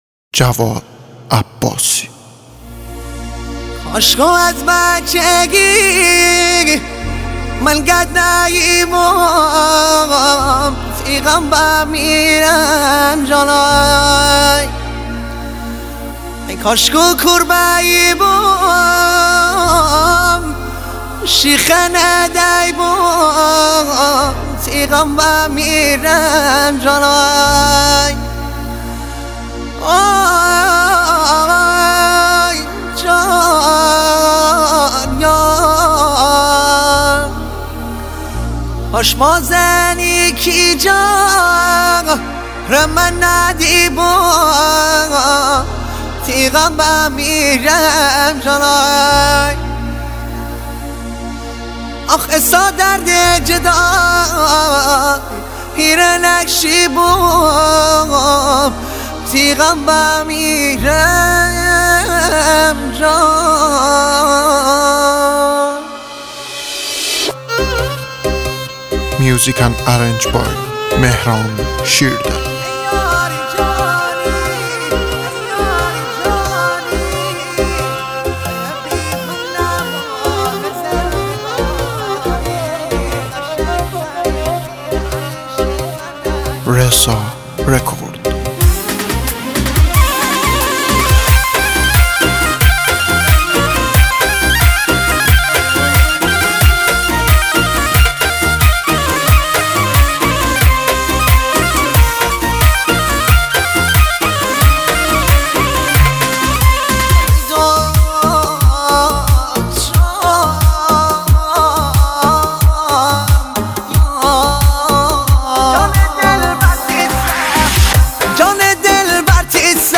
ریمیکس شمالی